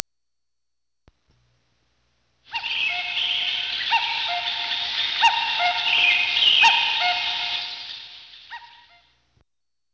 鉄軌道駅のプラットホーム上の階段
カッコウ（京阪電気鉄道株式会社提供）
oto_guidekakko.wav